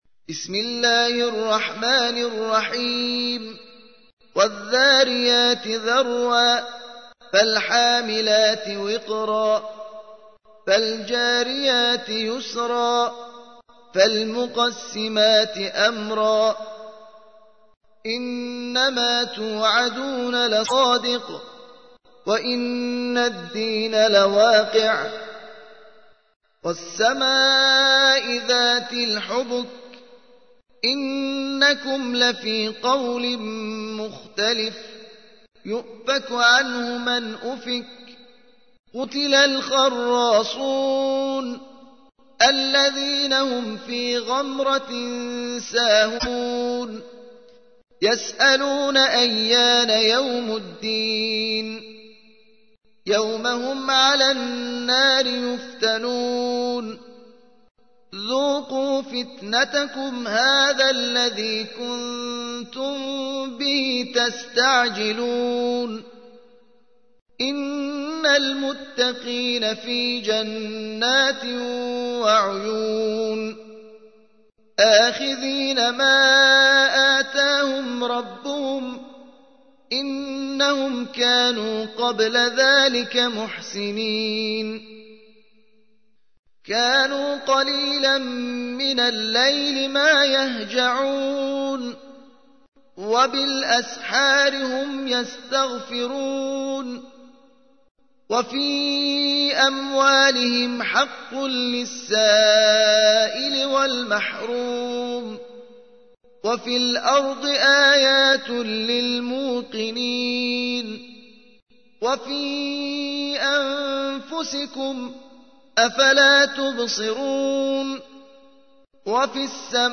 51. سورة الذاريات / القارئ